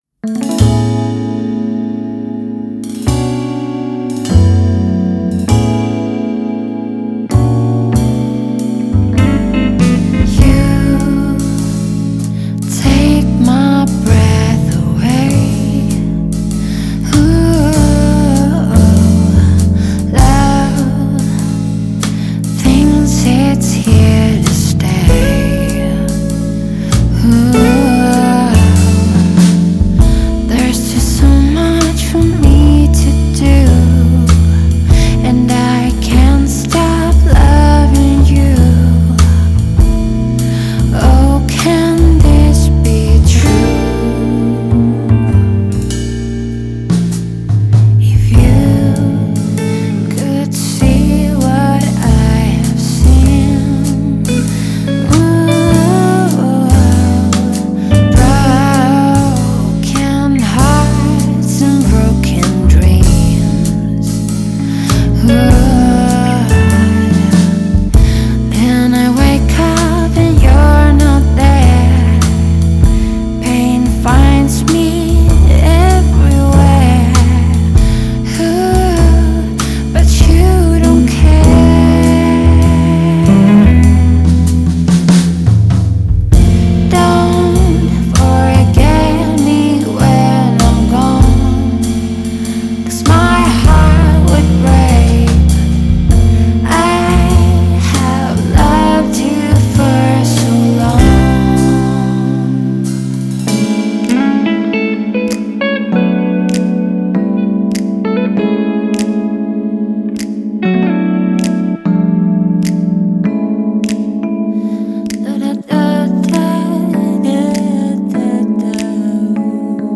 Jazz and '80s